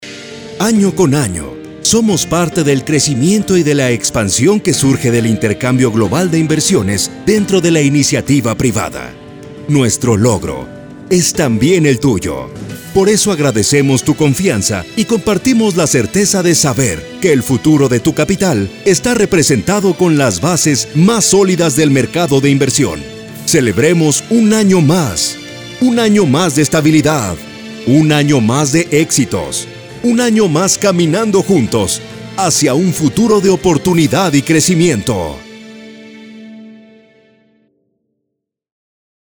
Corporate Videos
Styles: Warm, Elegant, Natural, Conversational, Sales Man, Corporate, Legal, Deep.
Equipment: Neumann TLM 103, Focusrite Scarlett, Aphex Channel, Source Connect
BaritoneBassDeepLowVery Low